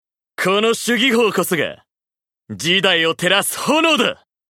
逆転の火付け役 しゅぜん（ぎほう） 朱 然 ［ 義封 ］ ＣＶ：柿原 徹也 サンプルボイス コメント 孫権の学友であったことから取り立てられた将。